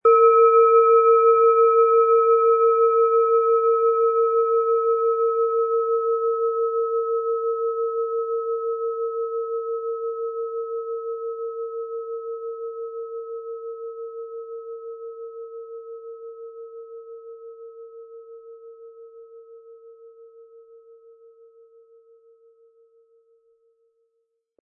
Planetenton
Wie klingt diese Planetenschale® Mond?
Der passende Schlegel ist umsonst dabei, er lässt die Schale voll und harmonisch tönen.
MaterialBronze